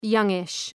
Προφορά
{‘jʌŋıʃ}
youngish.mp3